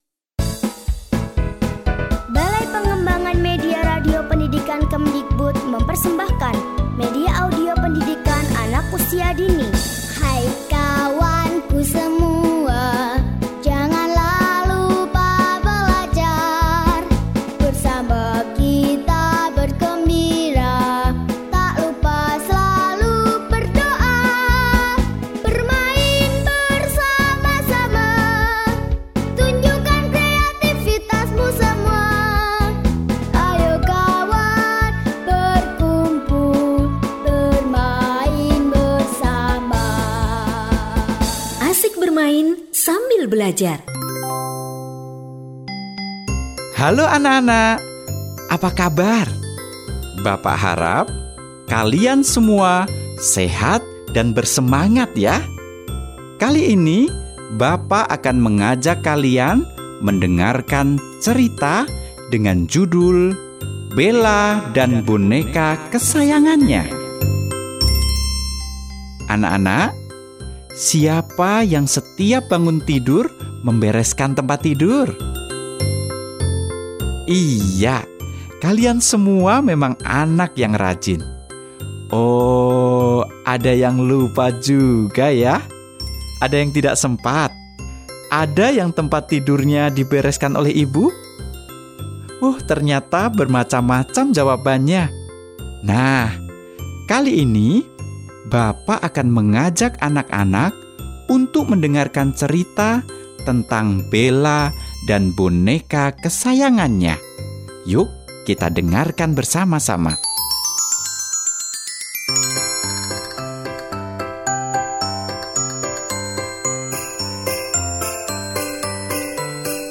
1852_MAPAUD_Cerita_-_Bella_dan_Boneka_Kesayangan.mp3